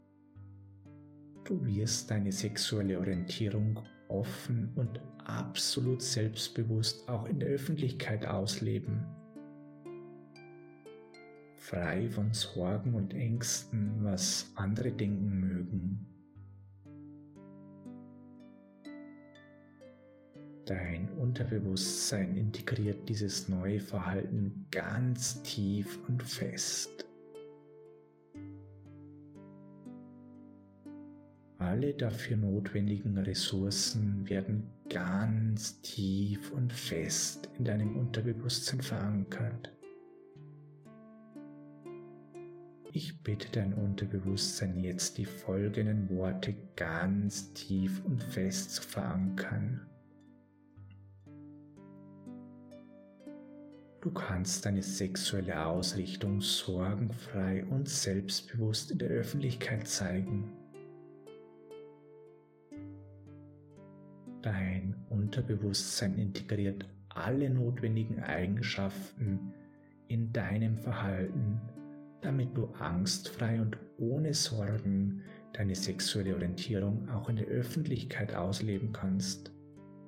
Die Stimme unseres erfahrenen Hypnotiseurs führt Sie tief in die Trance mit einer Einleitung und sukzessiver Vertiefung, so dass Ihr Unterbewusstsein Ihnen größeres Vertrauen und eine bessere Akzeptanz Ihrer sexuellen Orientierung geben kann.
Hörprobe: Q7001a – Homosexualität unbeschwert ausleben (für Männer)